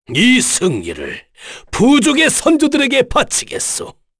Dakaris-Vox_Victory_kr_b.wav